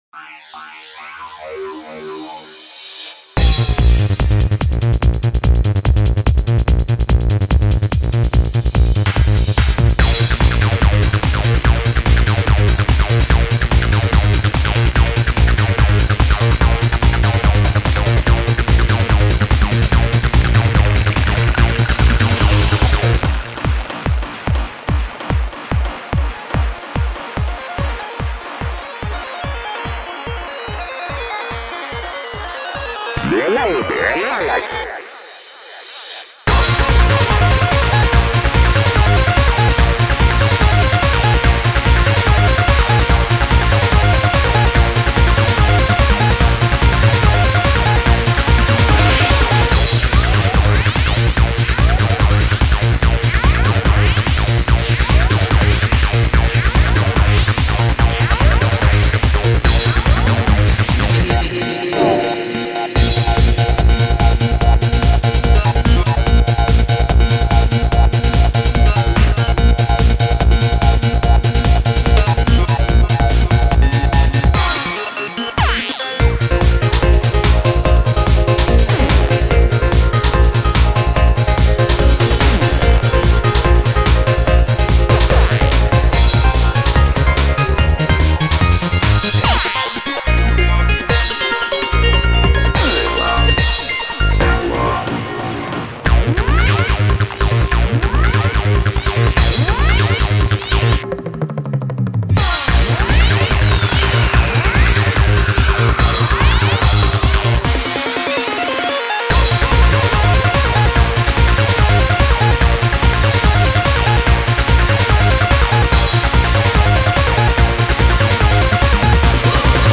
BPM145
Audio QualityPerfect (High Quality)
Comments[PSYCHEDELIC TRANCE]